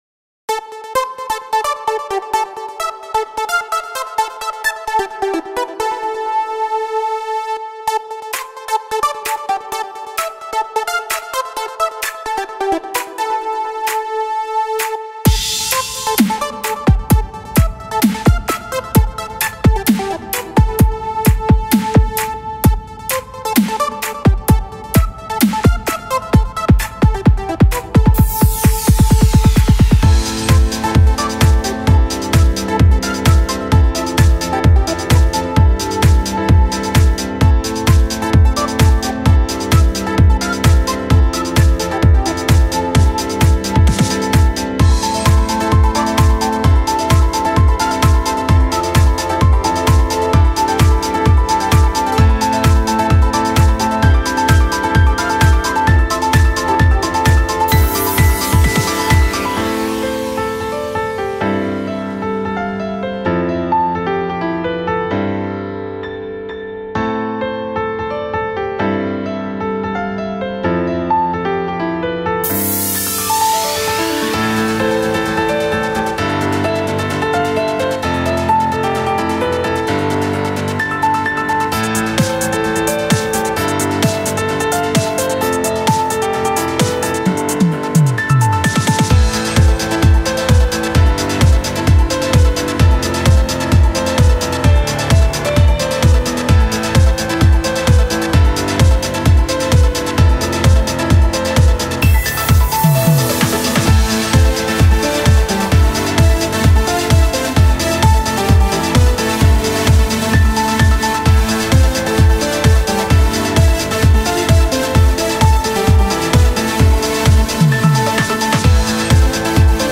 ورژن پیانو